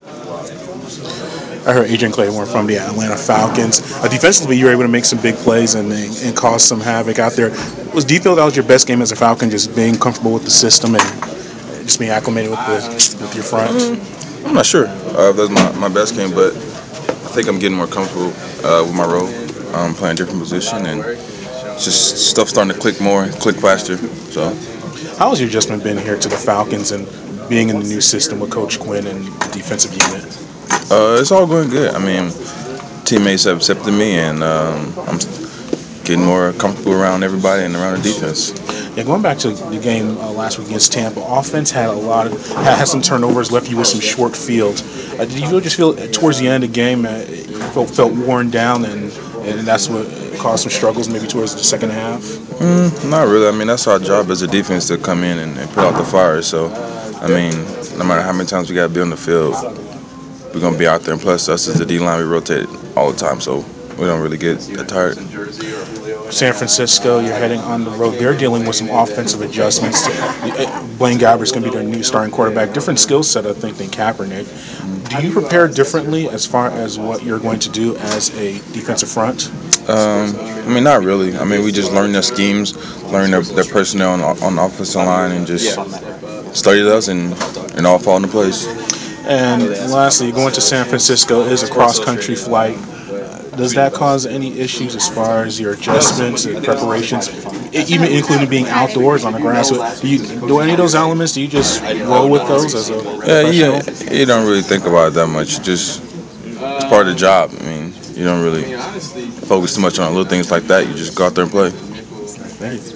The Sports Inquirer spoke with Atlanta Falcons’ defensive lineman Adrian Clayborn before his team’s practice on Nov. 4 for an exclusive interview.